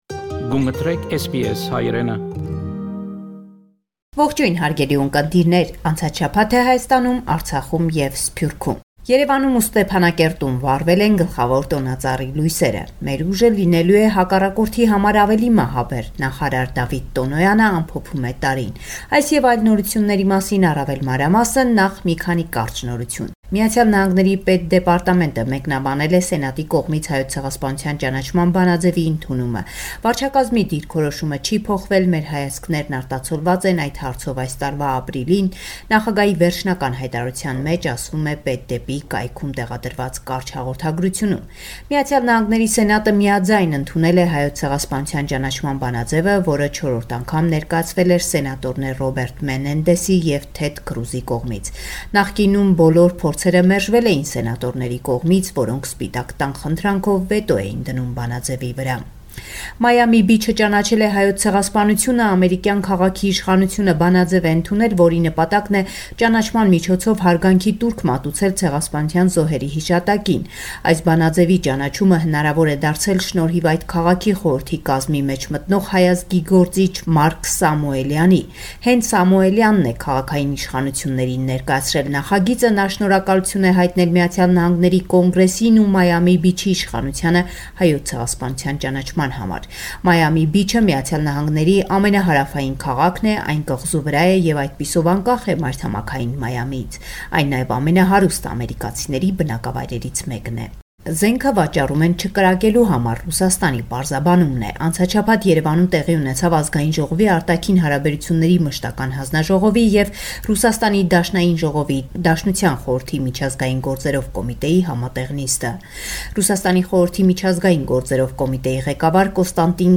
Վերջին Լուրերը Հայաստանէն – 24 Դեկտեմբեր 2019